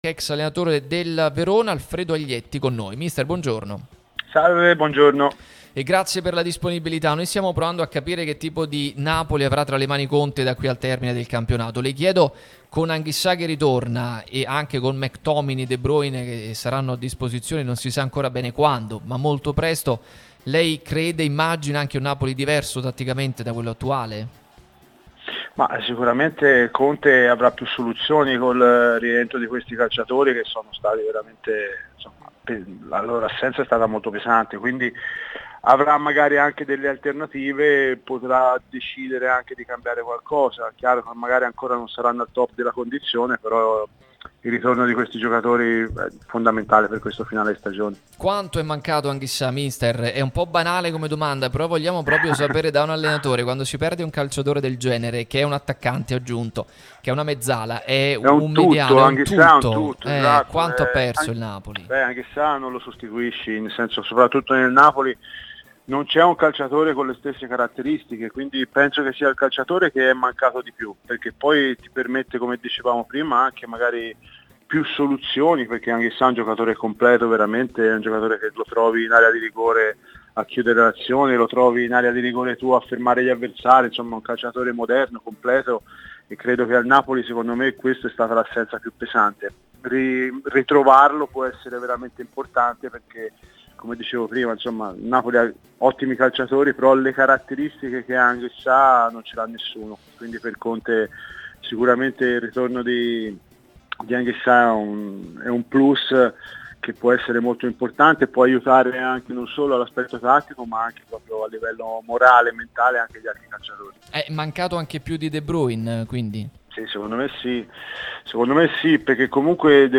è intervenuto nel corso di "Pausa Caffè" sulla nostra Radio Tutto Napoli